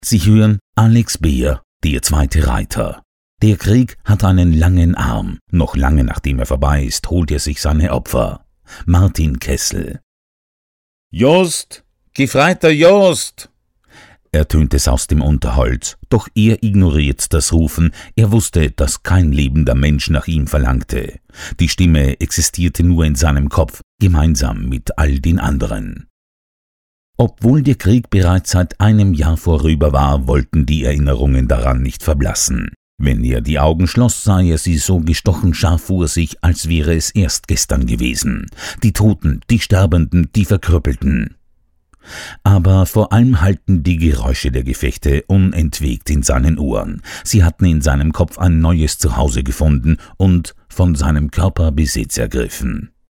Freundliche tiefe Männerstimme, akzentfrei und hochdeutsch.
Audiobooks
Mit Getragener Stimme